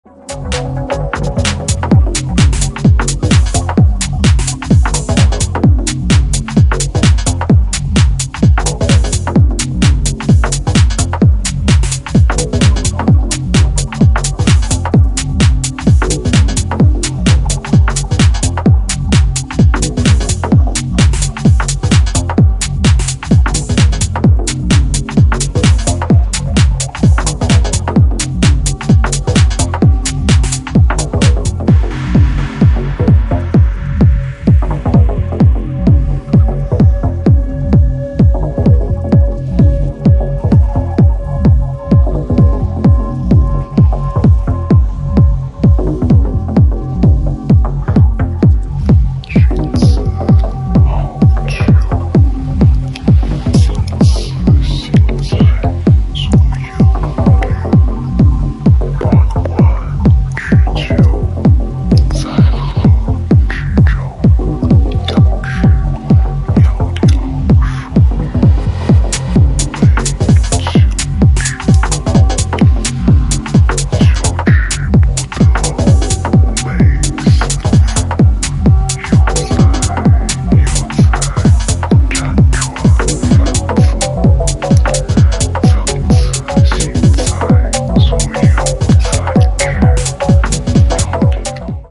raw-underground sound
a melting warper with a tough low end and chunky top lines